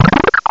cry_not_pidove.aif